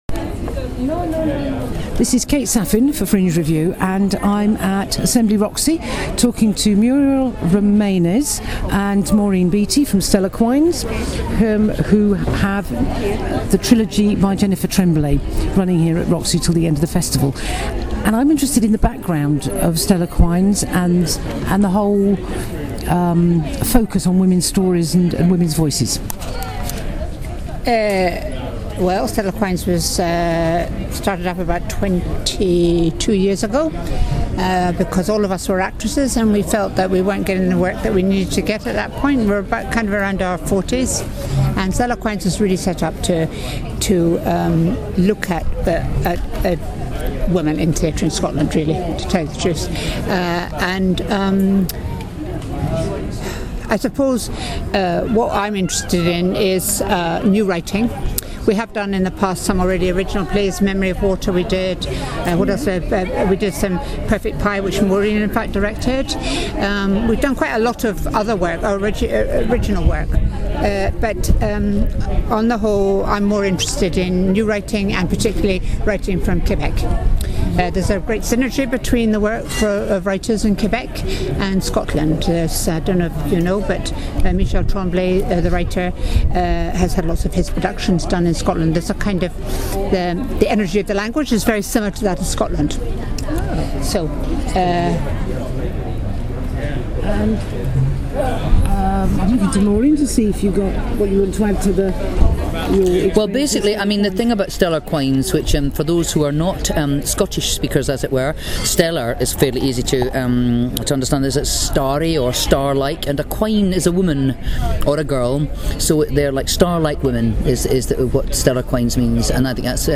We discussed the current production at the fringe – the Jennifer Tremblay Triology , especially The List, as well as the ways that Stellar Quines encourages and champions new writing. Listen to our interview